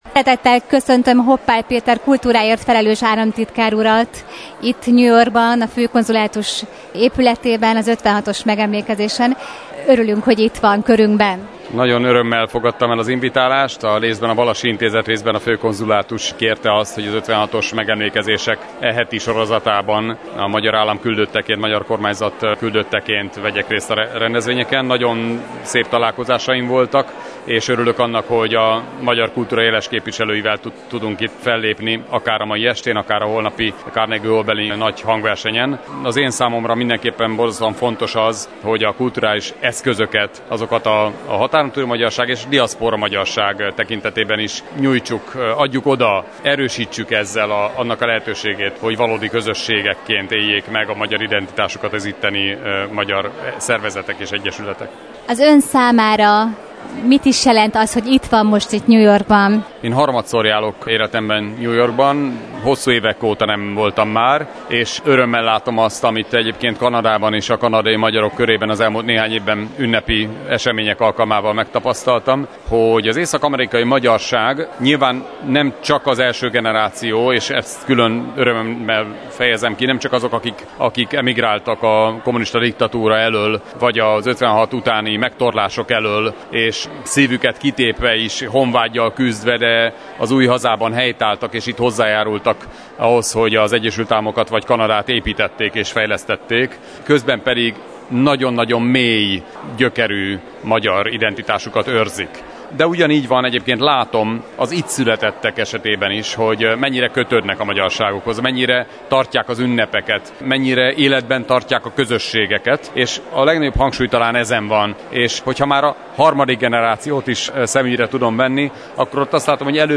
Interjú Dr. Hoppál Péterrel, kultúráért felelős államtitkárral – Bocskai Rádió